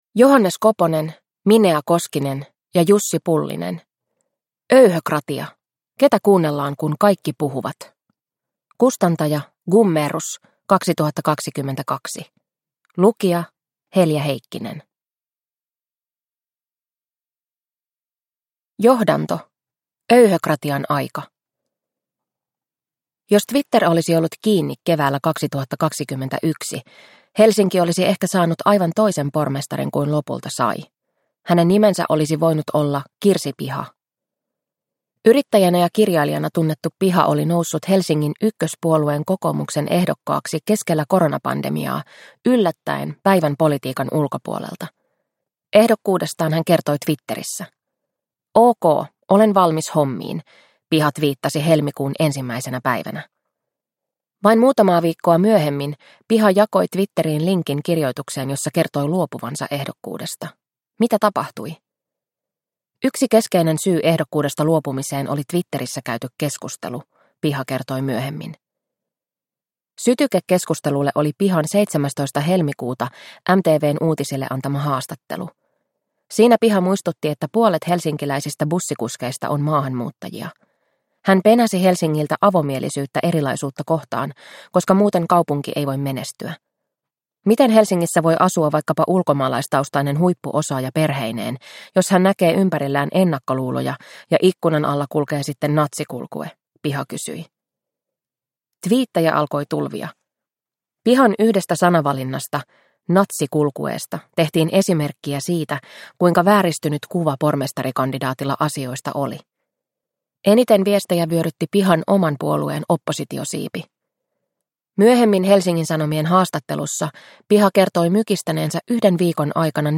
Öyhökratia – Ljudbok – Laddas ner